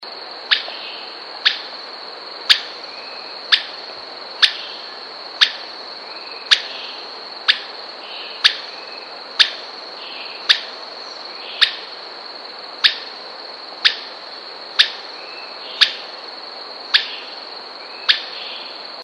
Benteveo Rayado Tuquito Chico
Myiodynastes maculatus Legatus leucophaius
Streaked Flycatcher Piratic Flycatcher